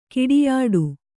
♪ kiḍiyāḍu